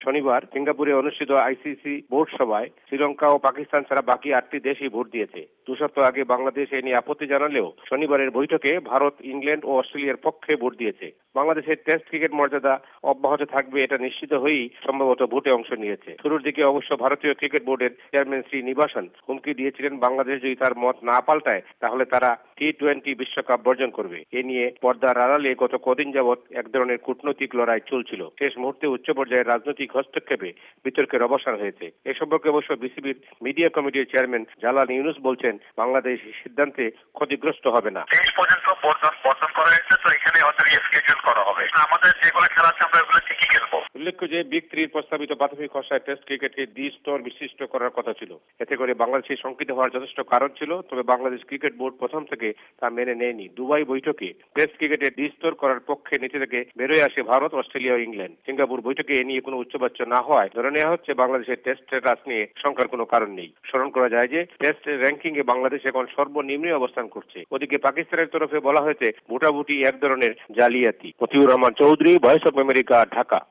বাংলাদেশ সংবাদদাতাদের রিপোর্ট